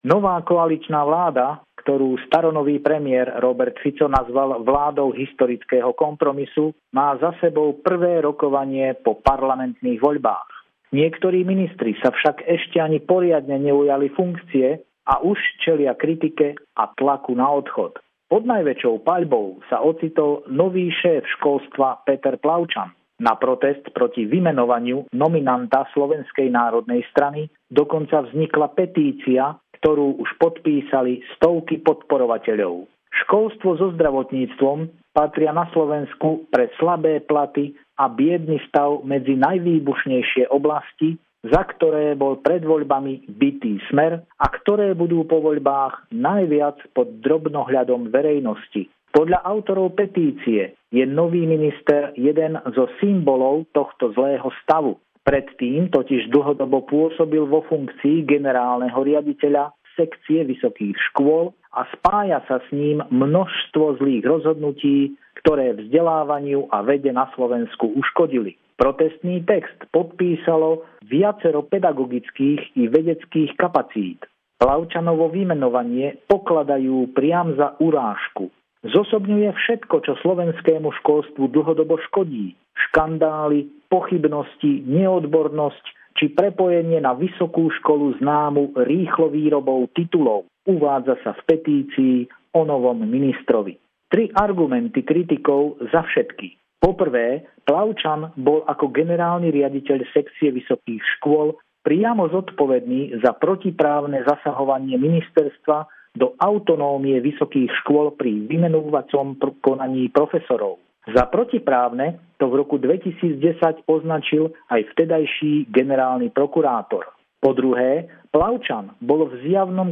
Pravidelný telefonát týždňa z Bratislavy